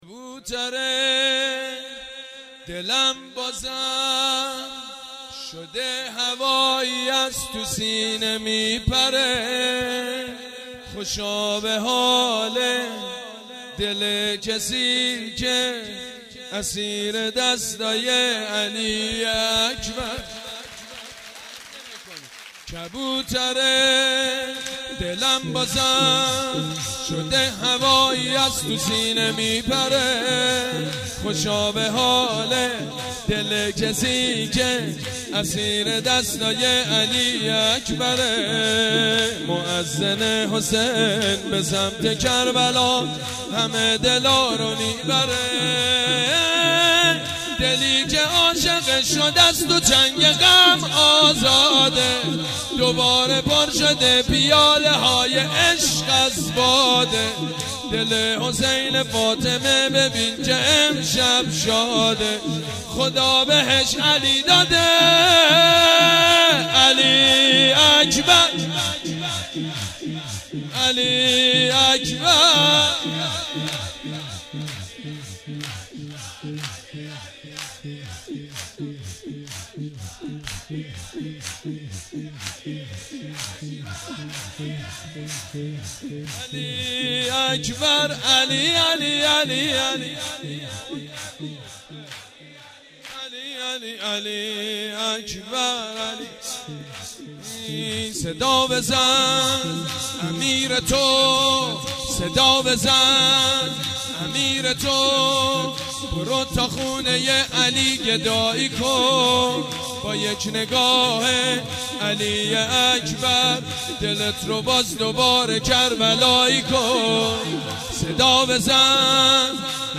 سرود: کبوترِ دلم بازم شده هوایی از تو سینه می پره
سرود: کبوترِ دلم بازم شده هوایی از تو سینه می پره خطیب: سید مجید بنی فاطمه مدت زمان: 00:02:54